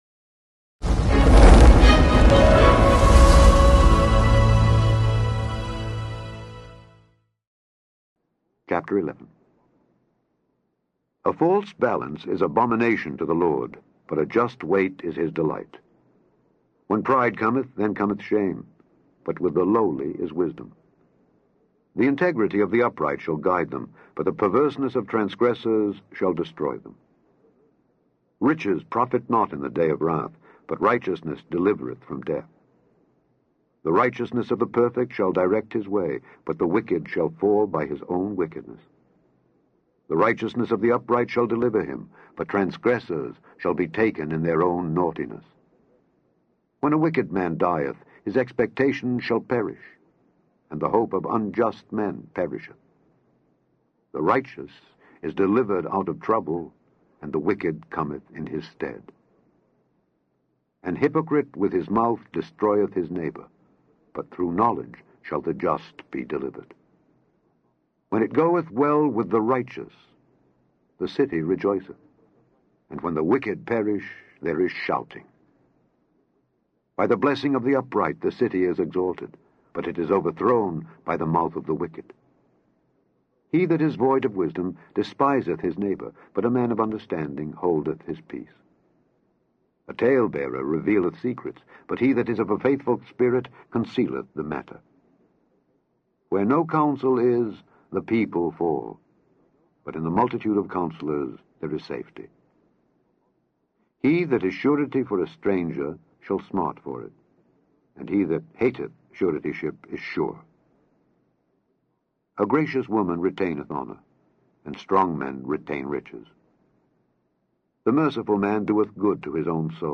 In this podcast, you can listen to Alexander Scourby read Proverbs 11-12.